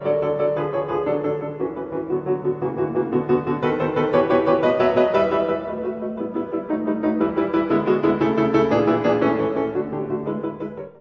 mp3Tchaikovsky, Peter, Album for the Young, No. 4 The Little Horseman, Vivo, mm.22-41